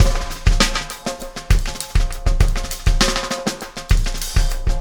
Extra Terrestrial Beat 13.wav